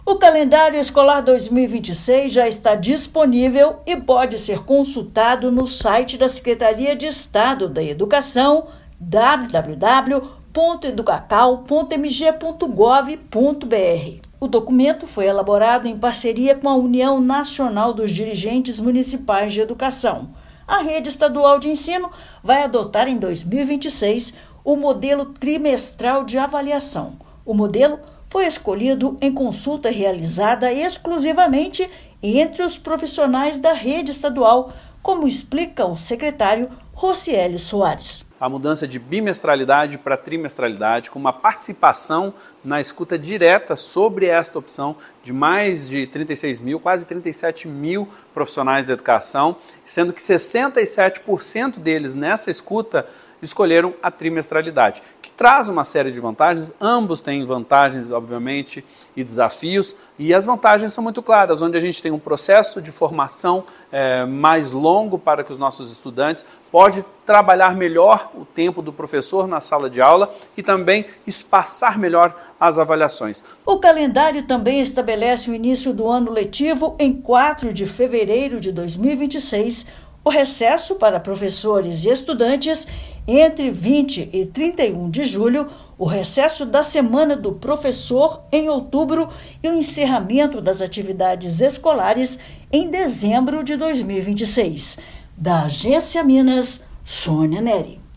Calendário unificado integra redes estadual e municipais; modelo trimestral, definido por consulta, vale exclusivamente para a rede estadual. Ouça matéria de rádio.